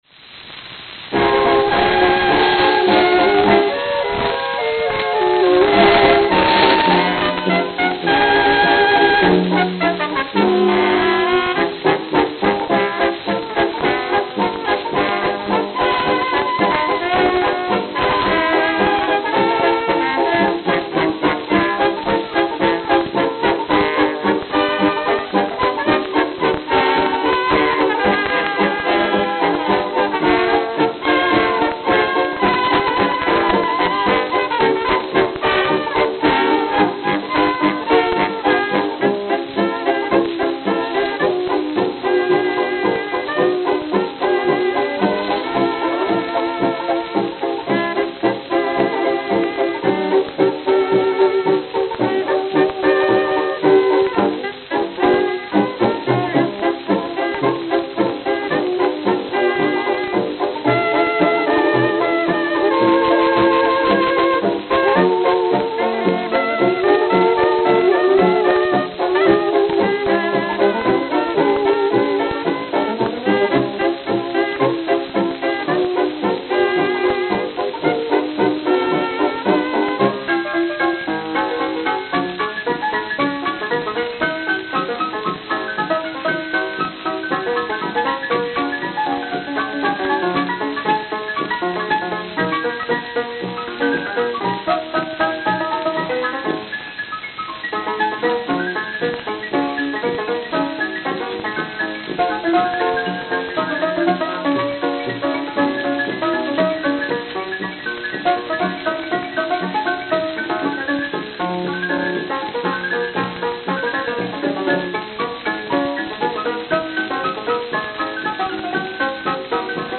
New York, New York New York, New York
Note: Very worn.